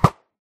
bow.ogg